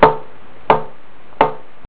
The easy way to tell what kind of Everglide mat you've got is to dangle it from two fingers and rap it with a knuckle.
When I tapped the RatpadzGS, it made
Not a bong, but not a click, either.